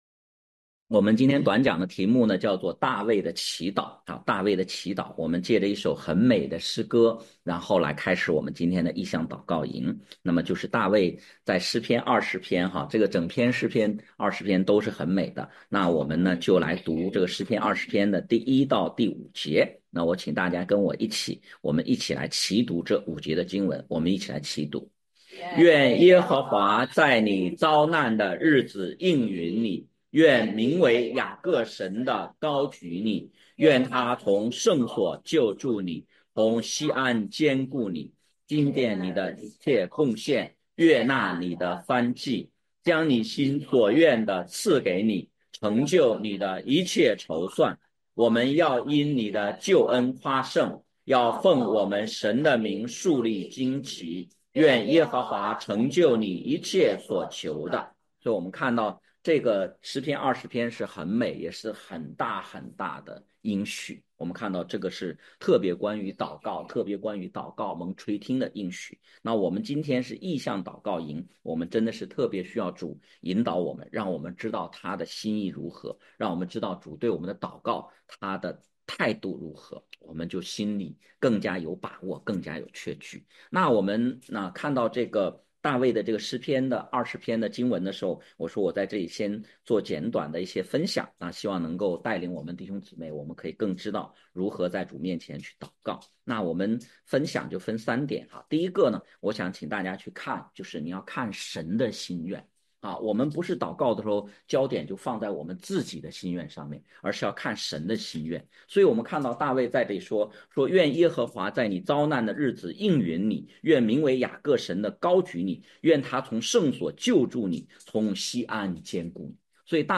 讲道录音 点击音频媒体前面的小三角“►”就可以播放： https